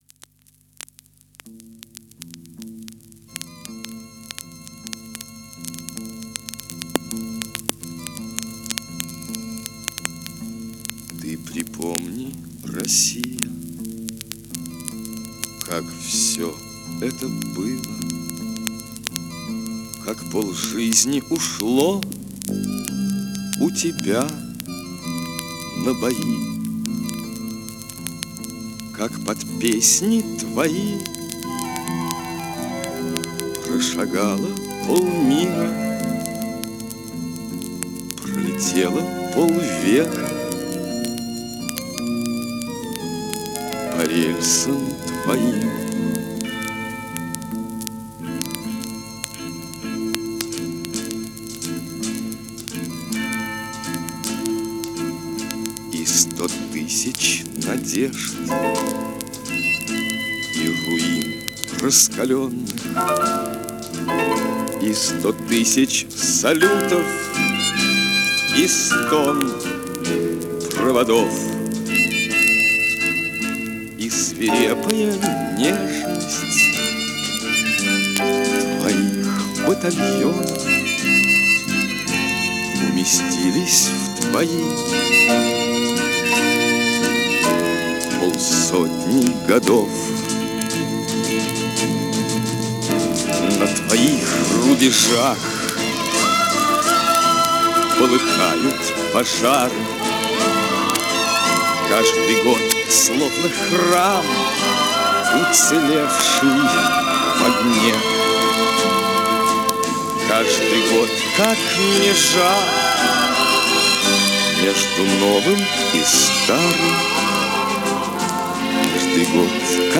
Песня
Перегон с грампластинки осуществлён в июле 2023 года